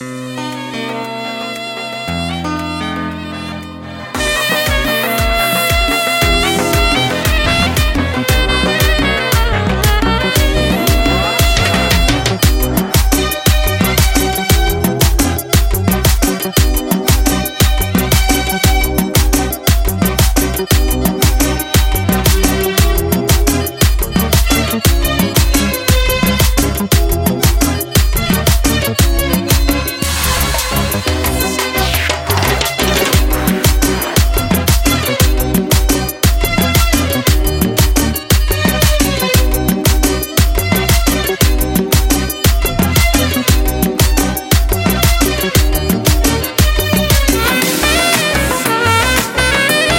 ویژگی‌های این موزیک بی‌کلام:
🎵 کیفیت صدای عالی و استودیویی
🎧 بدون افت کیفیت و نویز
پاپ
تلفیقی